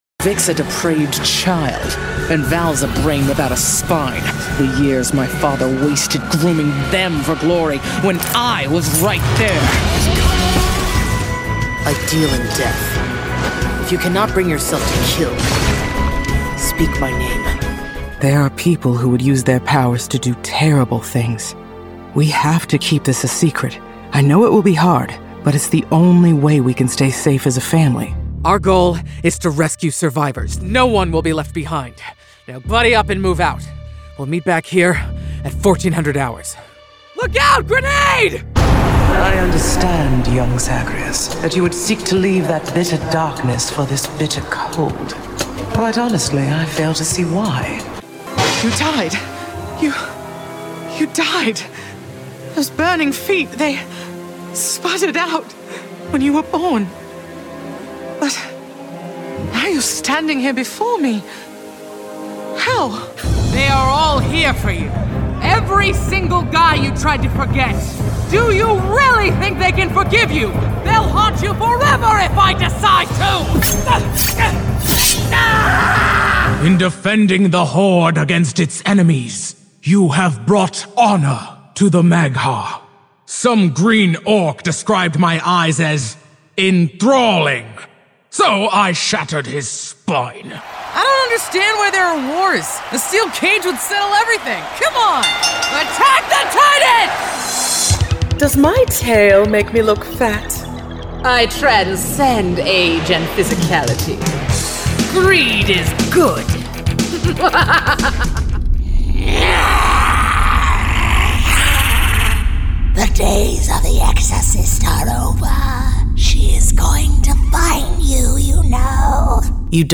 Villain - video games - biting - cold - powerful - badass - interactive - deep - harsh - seductive - angry Demon - monster - creature - exorcist - animal - texture - gutteral - spooky - scary - threatening - demonic Female ai - artificial intelligence - calm - serious - monotone - robotic - informative - slow